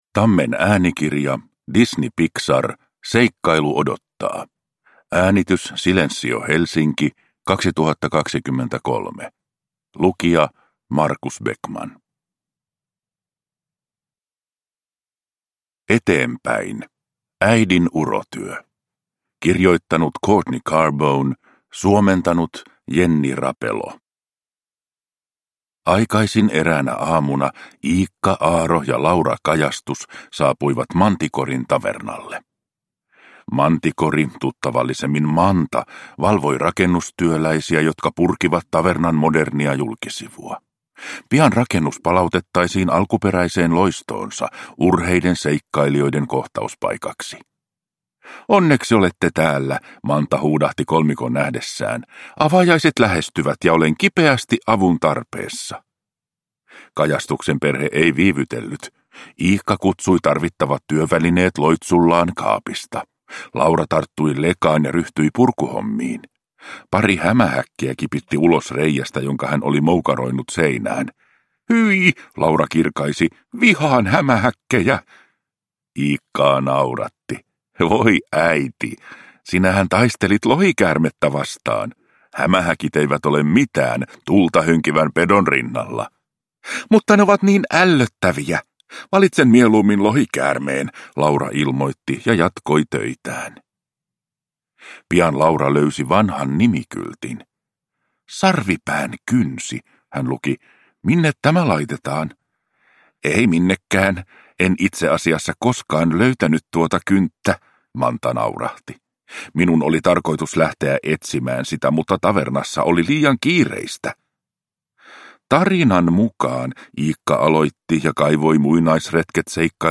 Disney Pixar. Seikkailu odottaa! – Ljudbok – Laddas ner